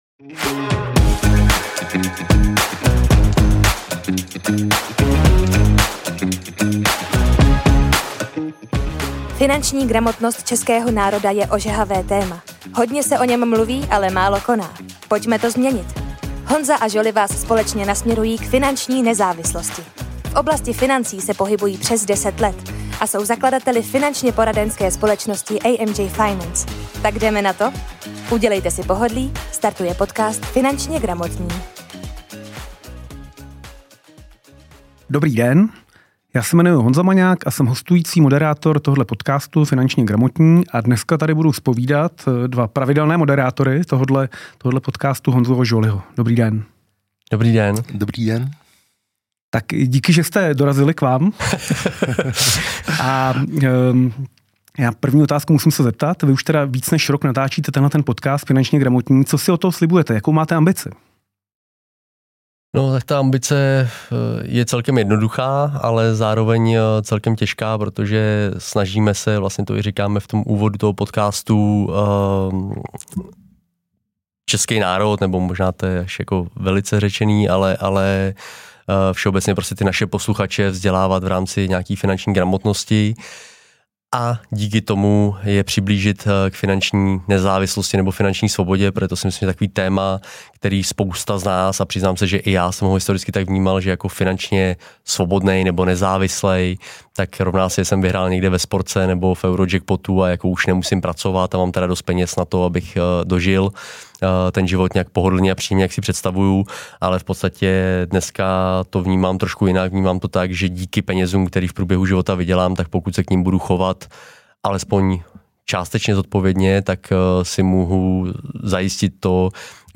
V rozhovoru se také dozvíte, jakému modelu v AMJ věří a proč sází na specializaci.